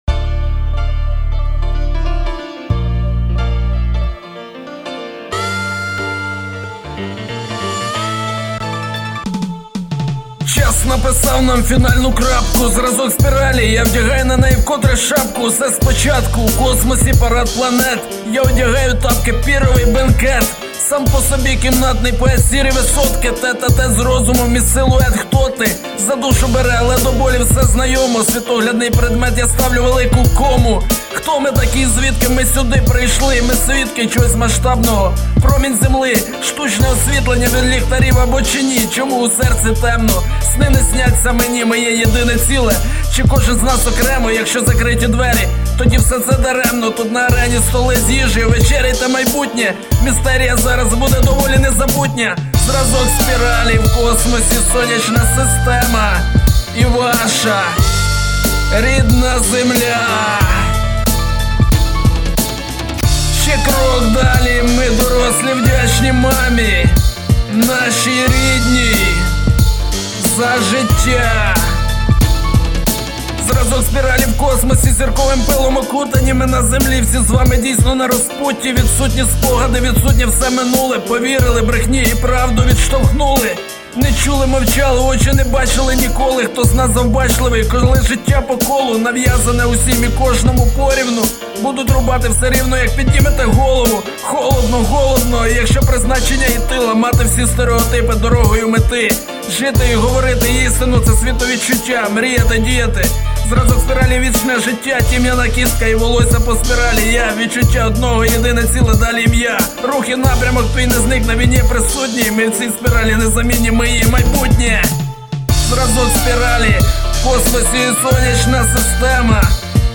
добре озвучив, молодець! 12